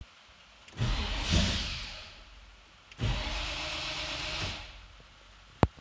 Took some quick recordings of the XS Torque starter.
The first one is cold and not giving it the single pump it needs as I wanted to hear just the cranking sound. You can hear the whirring after the first crank/release.
These are both taken inside the cab with everything closed up.
Yes, this is inside a metal shop building that even if it is misting outside it sounds like a downpour.
XSTorqueFord460-C6-NOSTART.wav